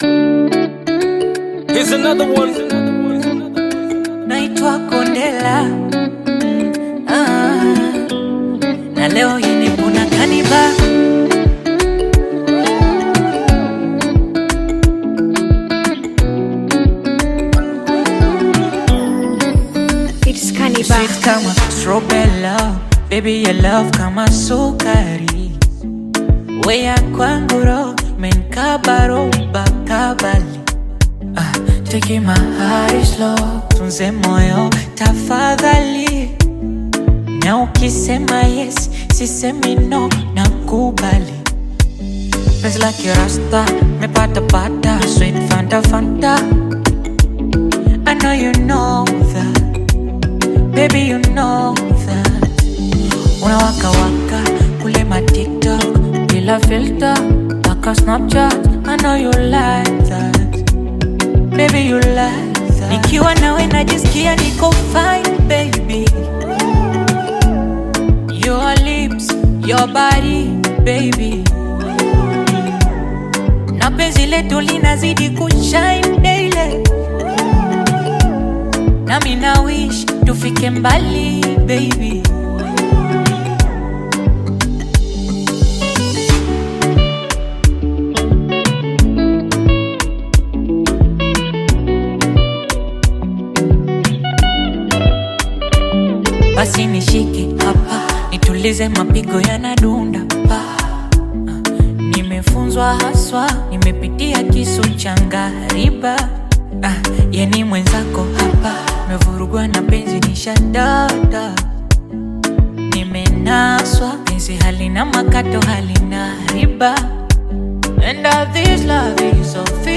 bongo flava love song
Bongo Flava